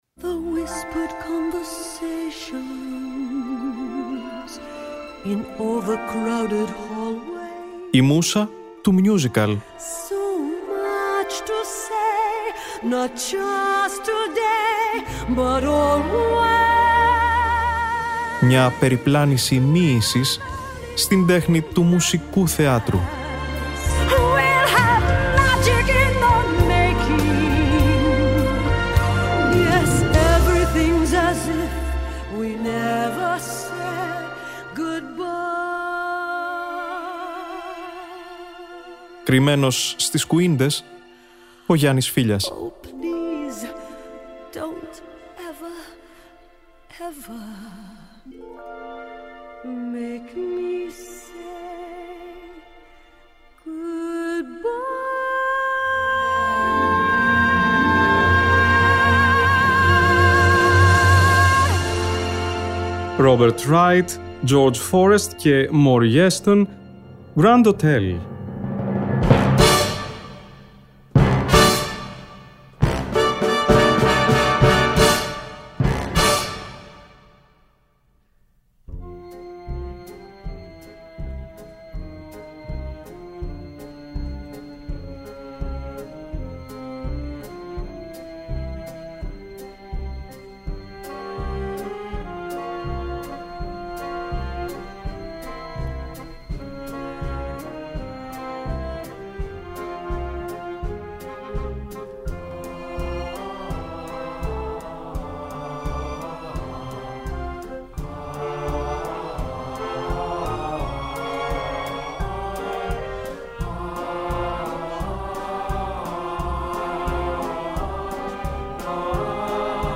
Broadway musical